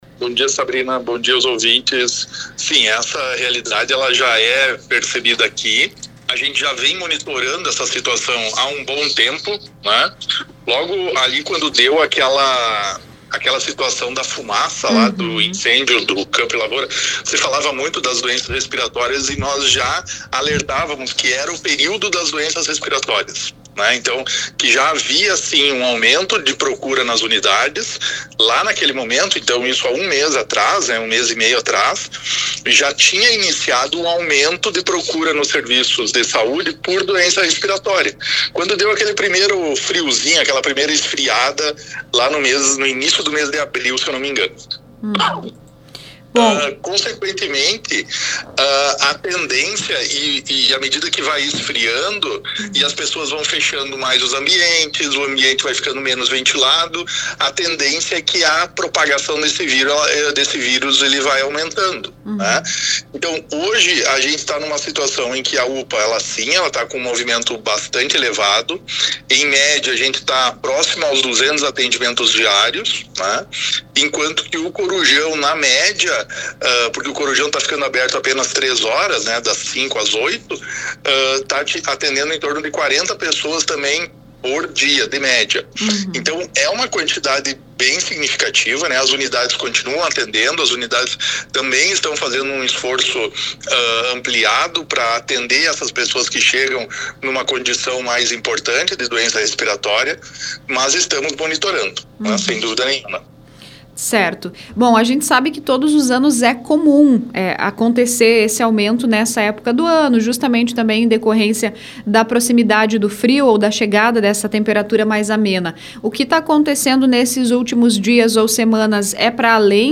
No entanto, conforme avaliou em entrevista à Rádio Progresso, Márcio afirma que o número de atendimentos segue dentro do esperado, acompanhando a mesma tendência do ano passado.